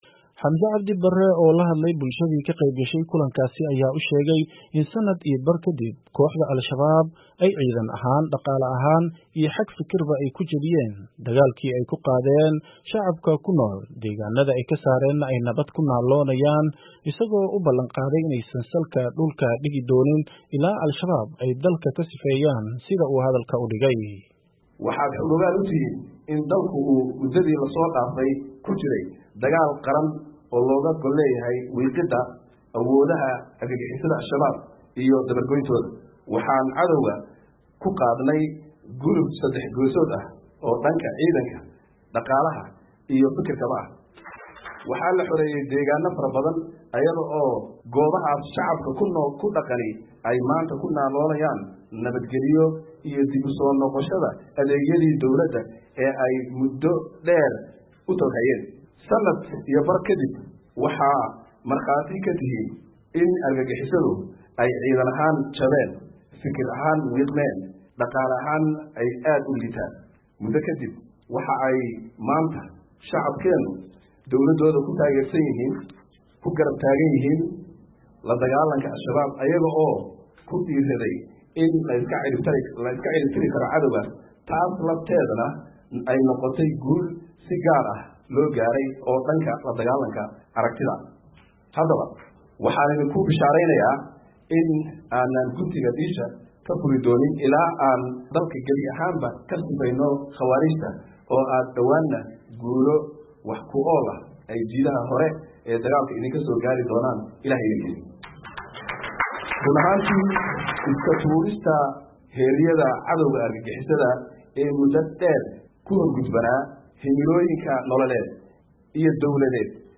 Warbixinga Khudbadda Ra'isul Wasaaraha